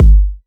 • Fat Steel Kick Drum G Key 543.wav
Royality free kickdrum tuned to the G note. Loudest frequency: 96Hz
fat-steel-kick-drum-g-key-543-OfI.wav